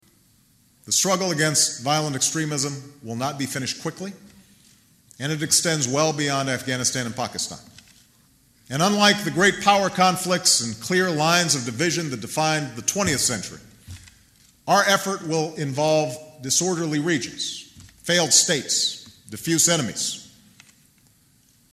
Tags: Travel Asyndeton Figure of Speech Brachylogia Speeches